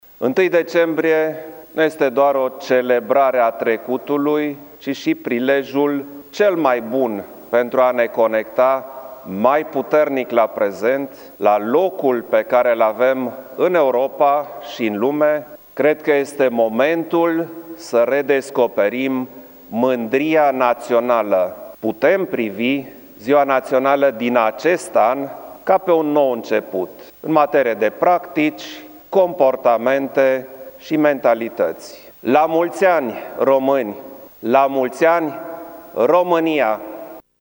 Ziua Naţională trebuie să marcheze un nou început, după un an marcat de tragedii, care au arătat ce lucruri nu funcţionează în România şi care au scos la iveală o „profundă dorinţă de schimbare” căreia politicienii şi partidele trebuie să-i răspundă, afirmă președintele Iohannis în mesajul de Ziua Națională a României: